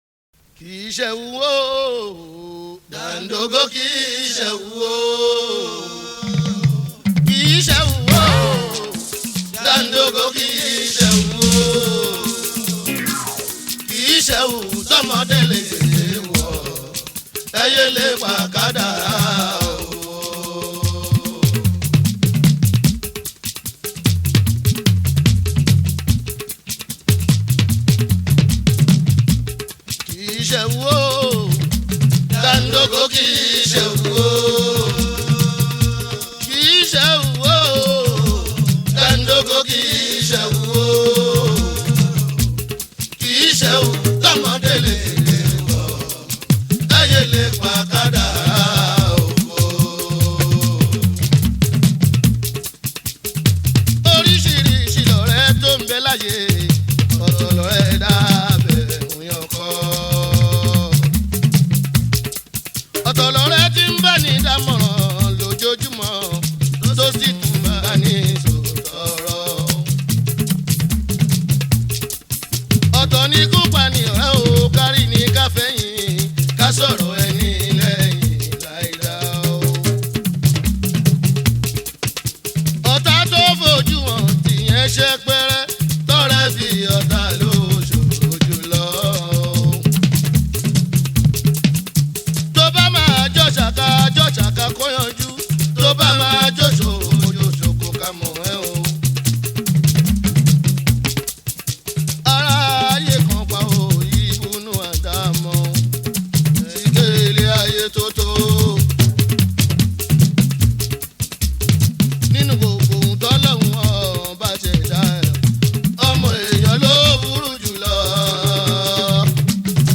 Yoruba Fuji song
Fuji song